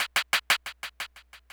percussion loop.wav